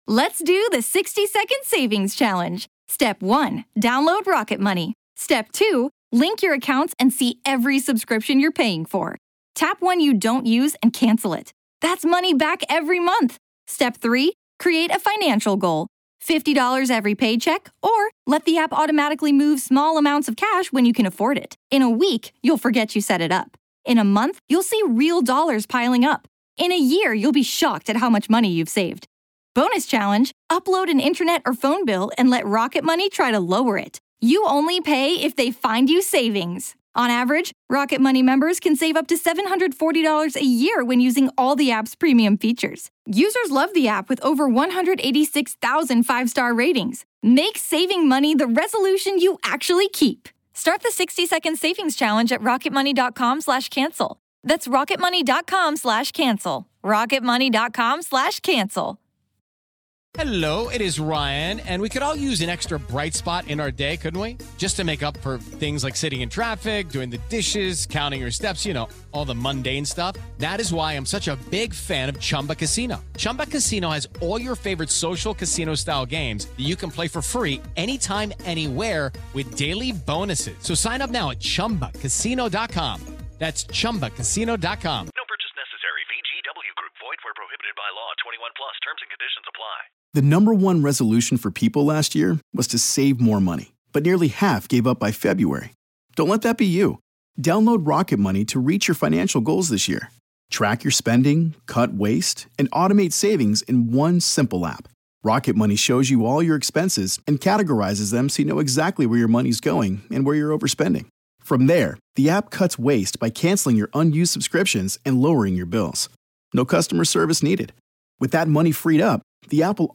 Haunting real ghost stories told by the very people who experienced these very real ghost stories.